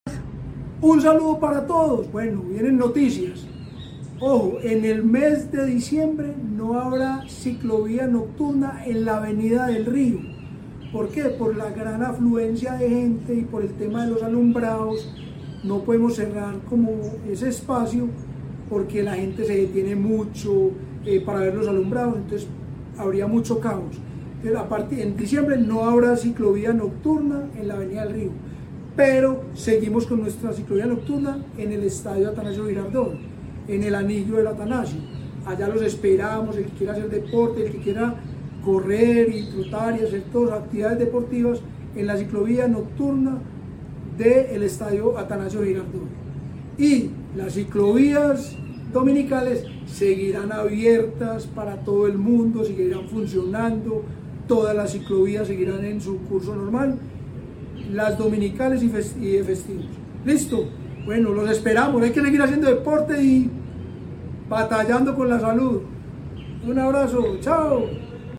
Declaraciones del director del Inder, Eduardo Silva Meluk La Alcaldía de Medellín invita a la comunidad a programar sus recorridos con anticipación y a consultar los canales oficiales del Inder .
Declaraciones-del-director-del-Inder-Eduardo-Silva-Meluk.mp3